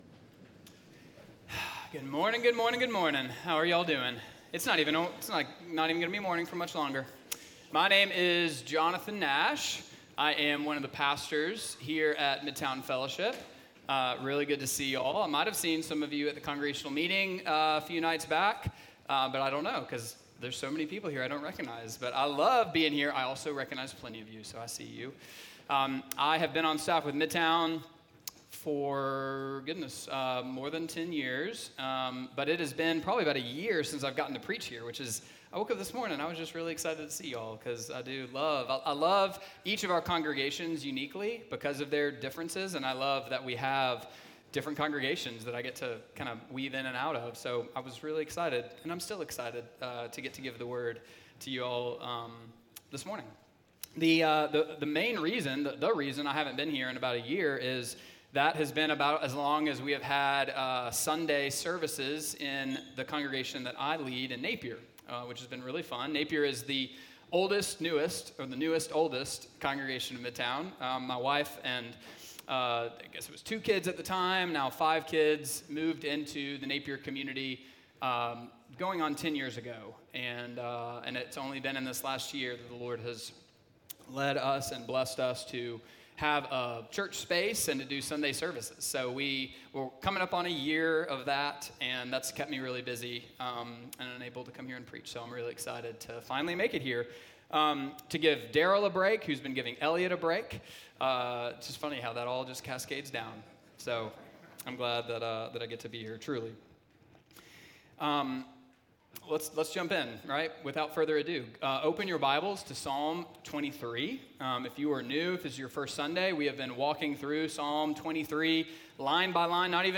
Midtown Fellowship 12 South Sermons My Cup Runneth Over Jul 28 2024 | 00:37:11 1x 00:00 / 00:37:11 Subscribe Share Apple Podcasts Spotify Overcast RSS Feed Share Link Embed